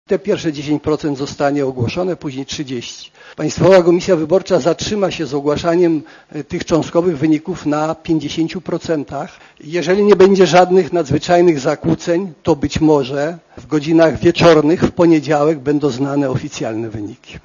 Mówi Ferdynad Rymarz (70Kb)